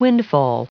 Prononciation audio / Fichier audio de WINDFALL en anglais
Prononciation du mot windfall en anglais (fichier audio)